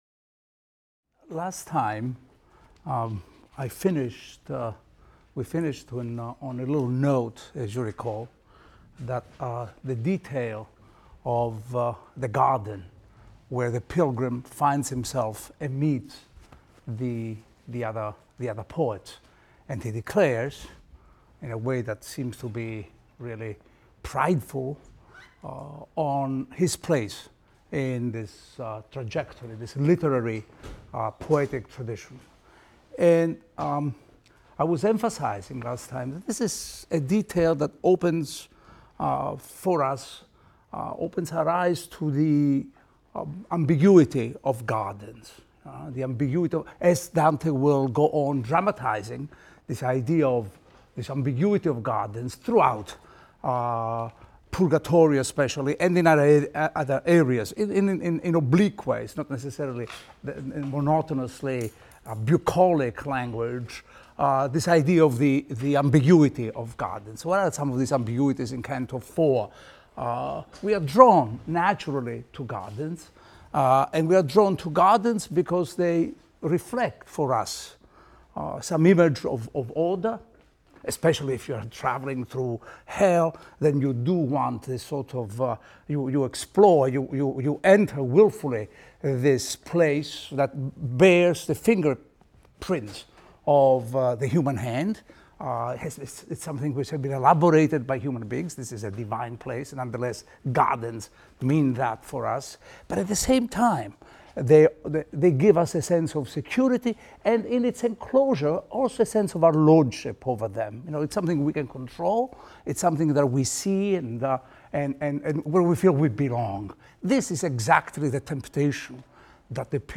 ITAL 310 - Lecture 4 - Inferno V, VI, VII | Open Yale Courses